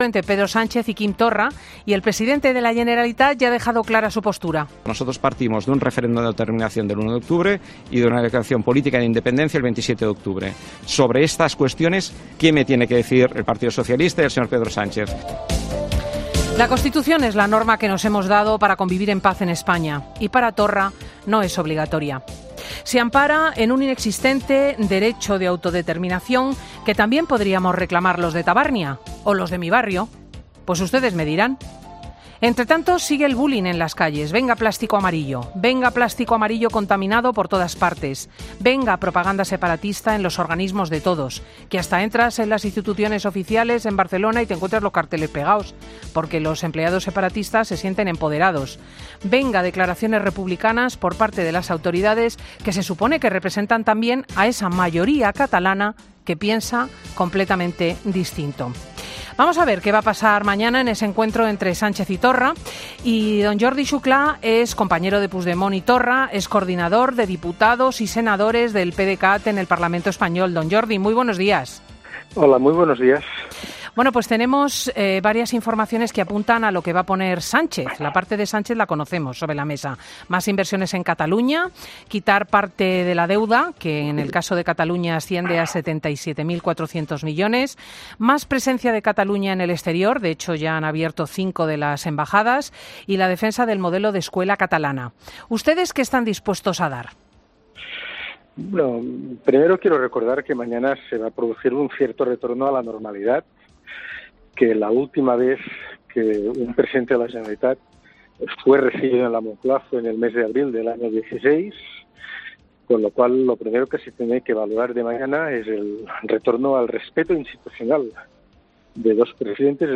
Jordi Xuclá habla de la reunión de Sánchez y Torra de este lunes en 'Fin de Semana'